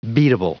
Prononciation du mot beatable en anglais (fichier audio)
Prononciation du mot : beatable